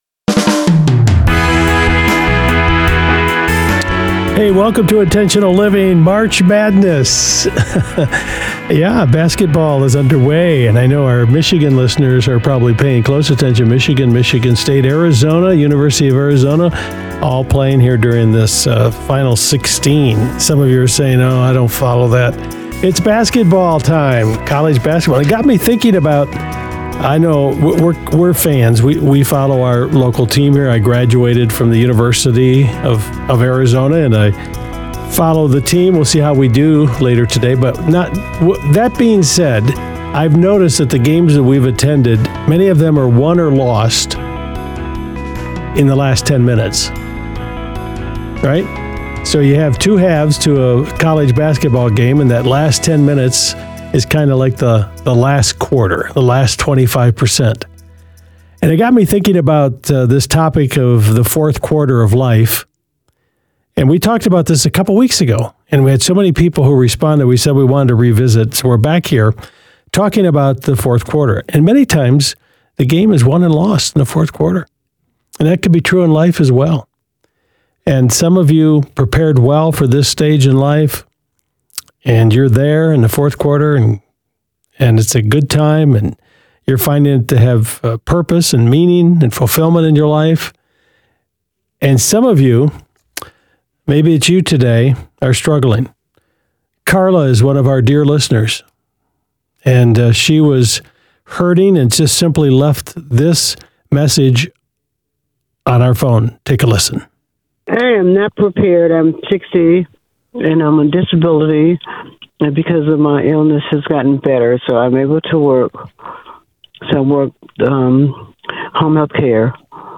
Intentional Living is a nationally-syndicated program on more than 250 radio stations including Family Life Radio.